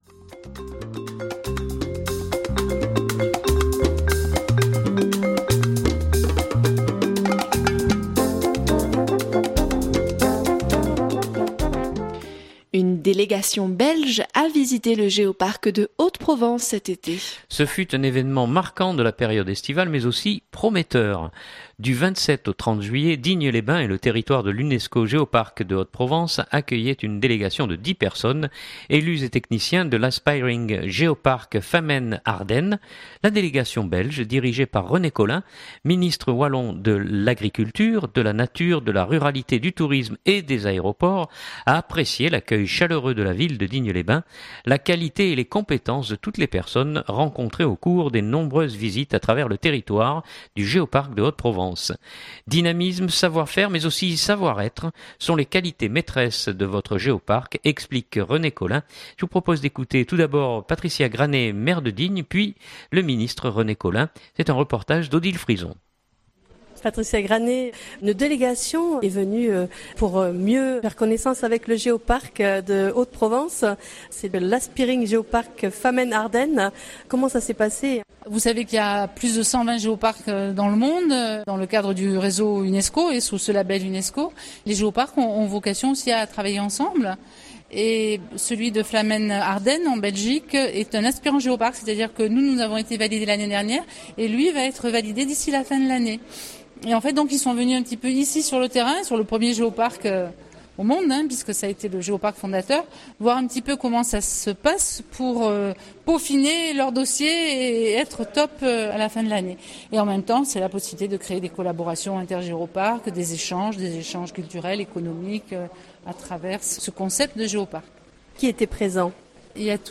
Je vous propose d’écouter tout d’abord Patricia Granet, maire de Digne, puis le Ministre René Collin. Reportage